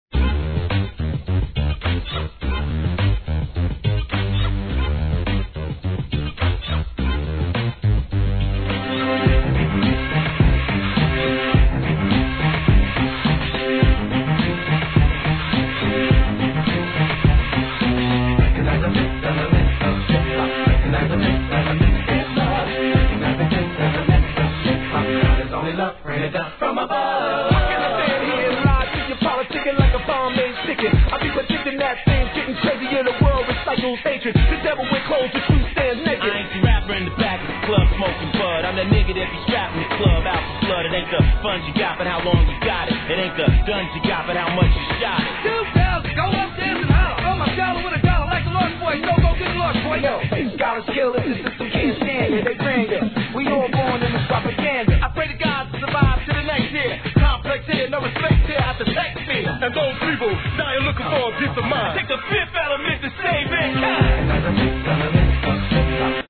HIP HOP/R&B
USインディーズ物ながら強姦MICリレーが聴けます!